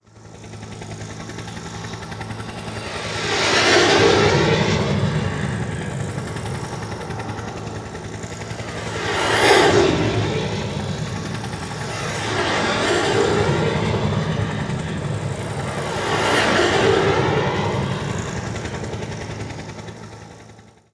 chop_h.wav